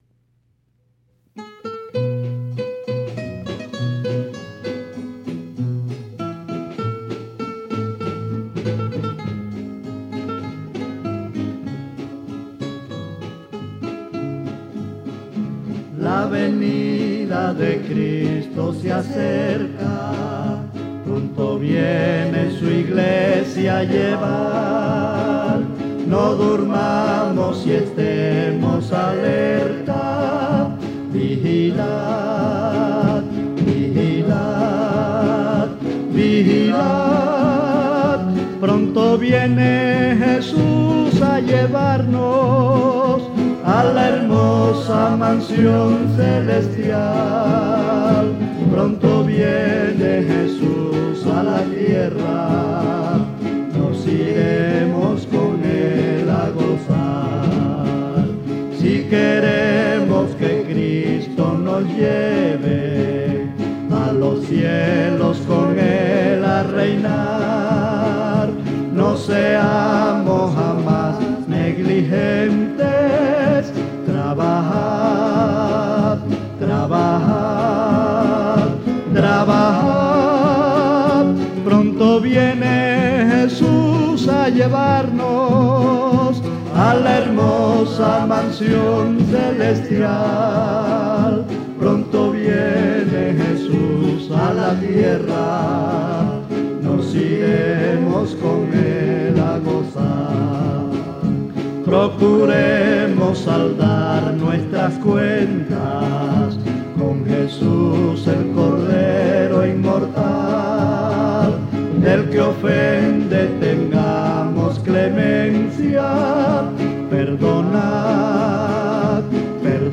Himno titulado